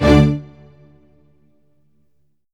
Orchestral Hits
ORCHHIT D3-R.wav